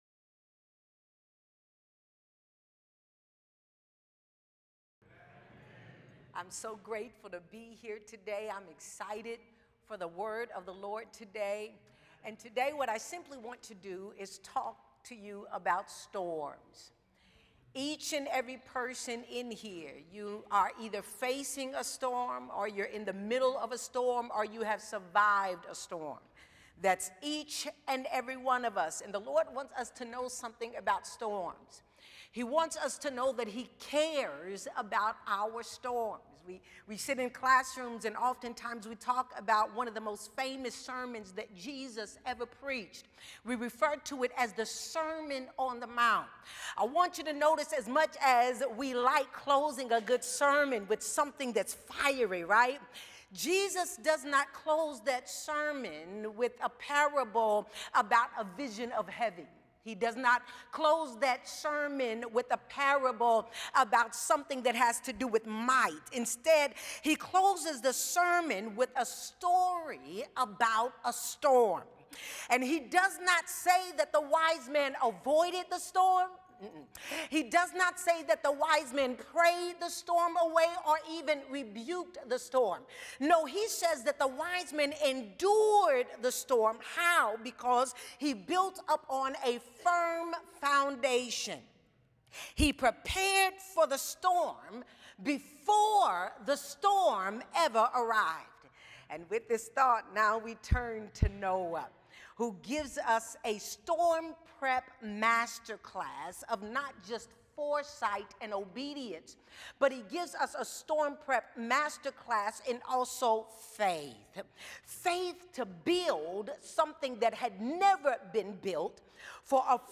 The following service took place on Wednesday, March 11, 2026.
Sermon